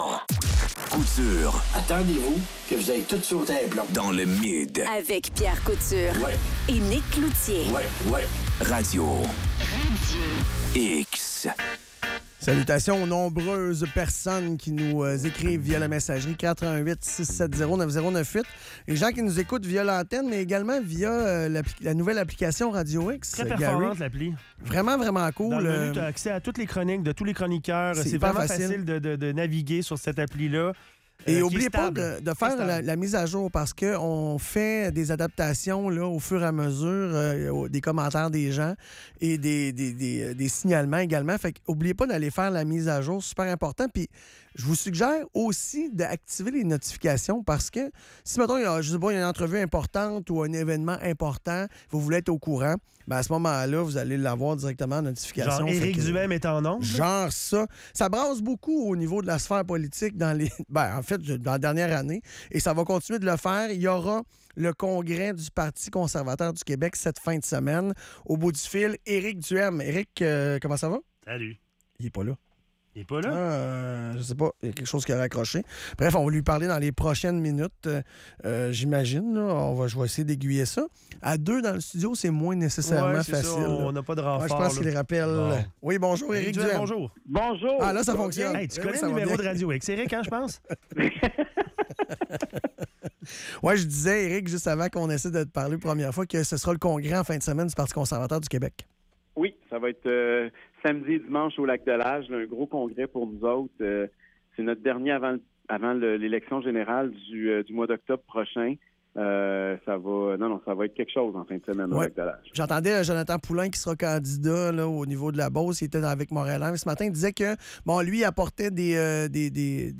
Entrevue avec Éric Duhaime du PCQ